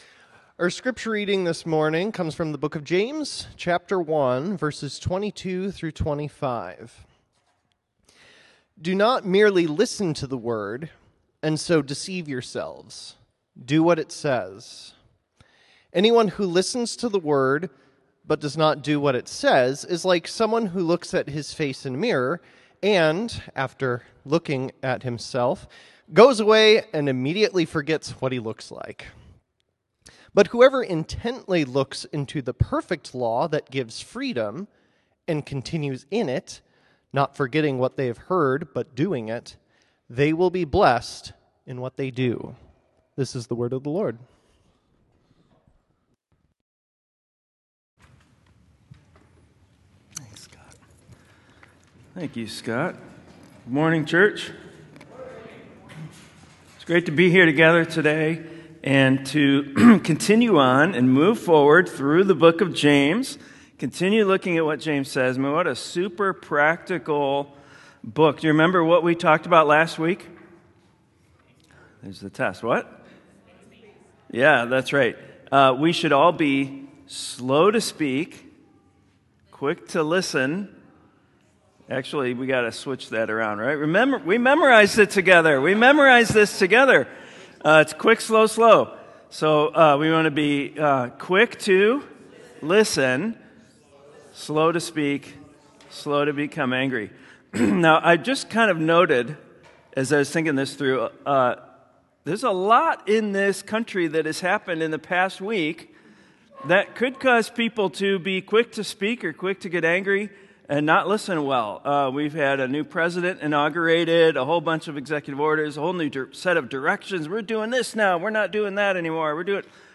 sermon-james-just-do-it.m4a